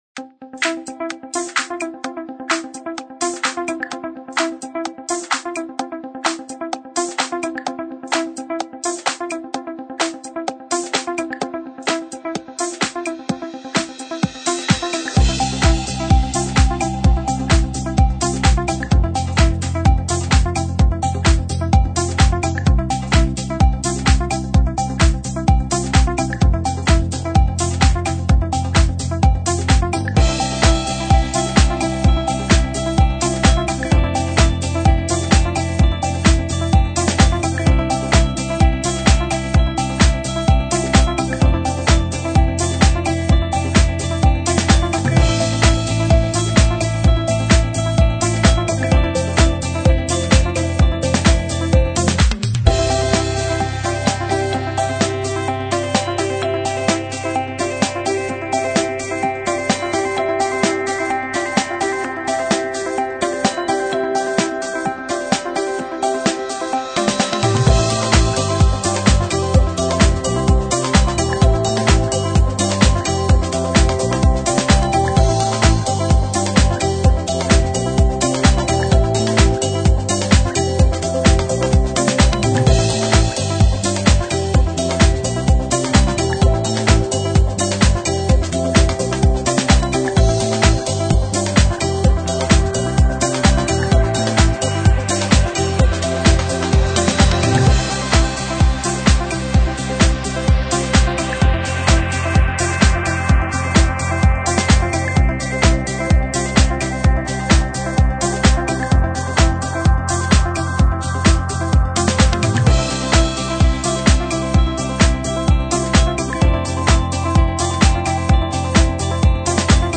描述：一个积极和令人振奋的企业动机轨道具有一个熟悉的和弦结构，四在地板上的节拍，延迟和琶音电吉他和大垫和合成材质。
Sample Rate 采样率16-Bit Stereo 16位立体声, 44.1 kHz